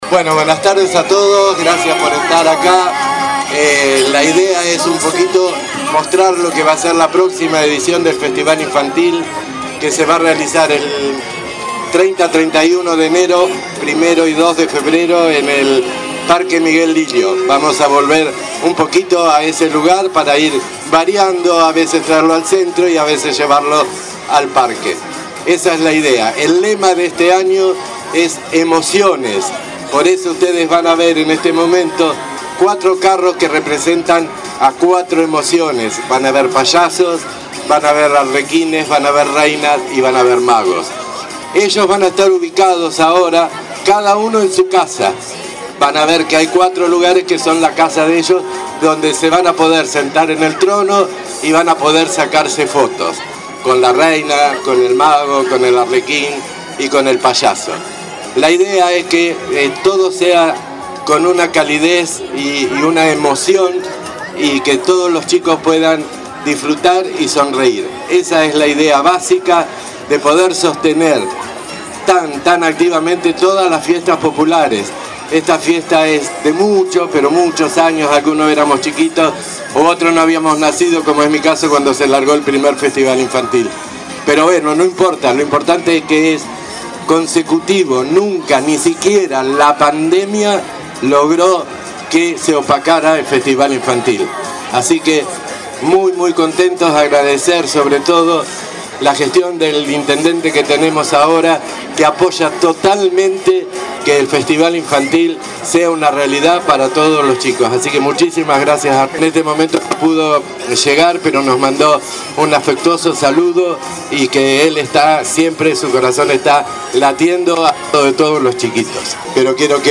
La cita ya fue fijada oficialmente: del 30 de enero al 2 de febrero de 2025 se realizará en el Parque Miguel Lillo la 64ºedición del Festival Infantil de Necochea. Así lo confirmó el director de Cultura, Juan Gamba, en medio de un desfile que sirvió como anticipo de lo que vendrá.